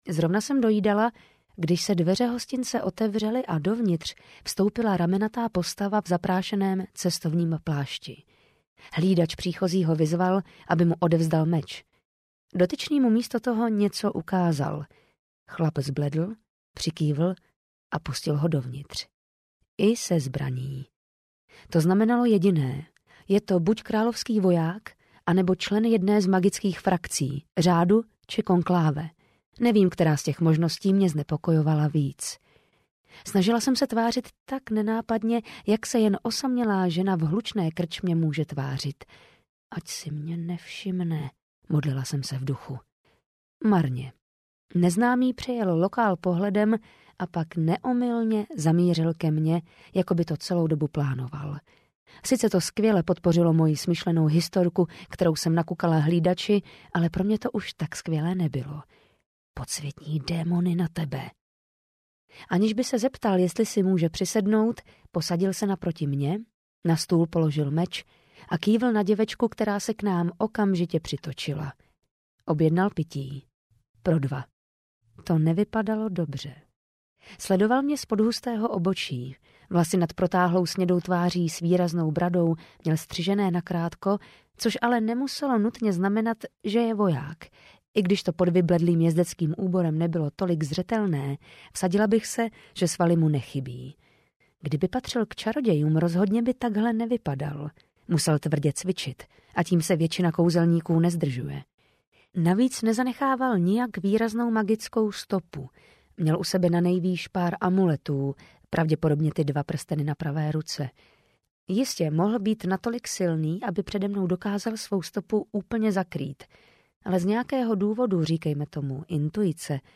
Noční labuť audiokniha
Ukázka z knihy